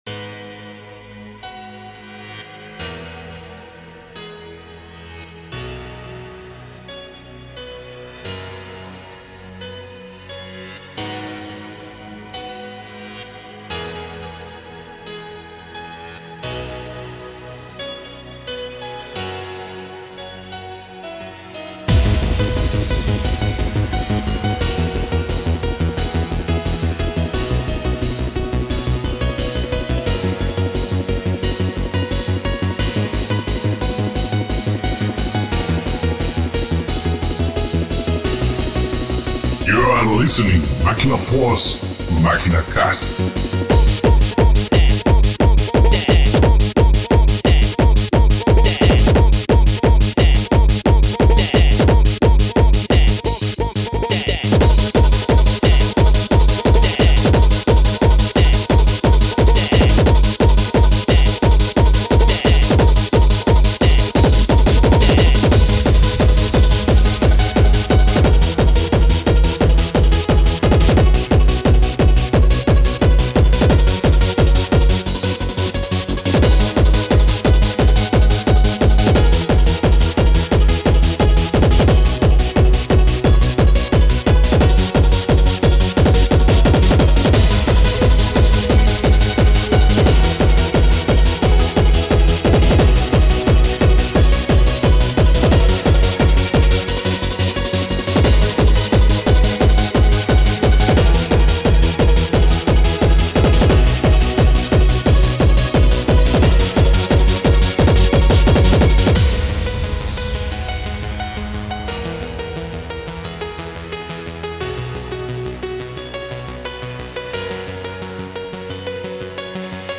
tribute makinacast